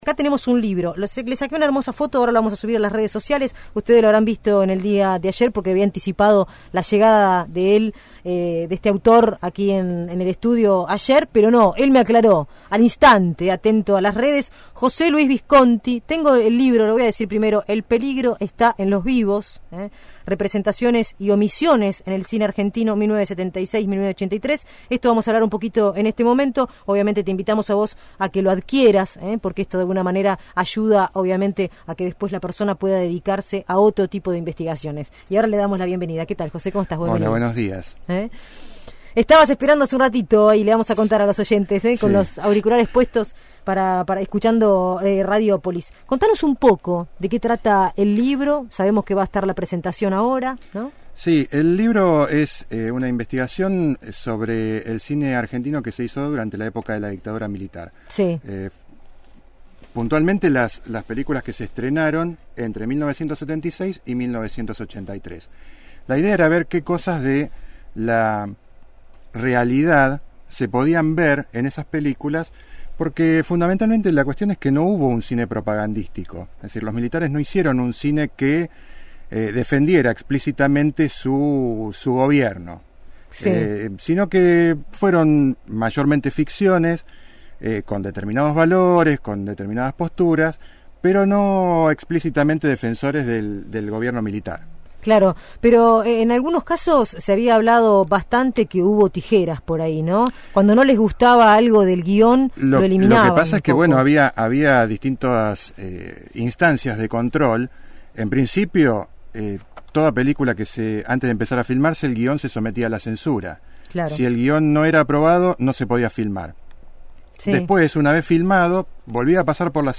que trata sobre el cine nacional del período 1976-1983, visitó el estudio de Radiópolis Ciudad Invadida